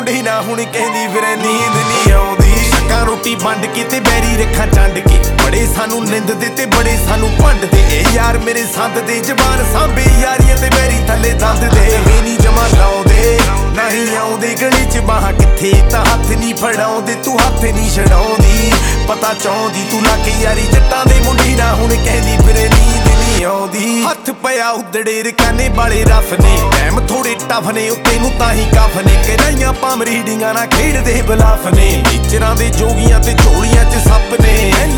Жанр: Поп / Нью-эйдж